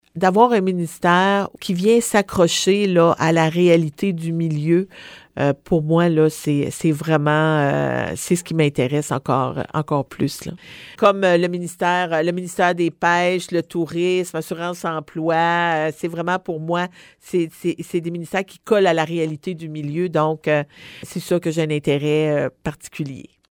La députée sortante de la Gaspésie-Les-Îles-de-la-Madeleine en conférence de presse à la marina de Gaspé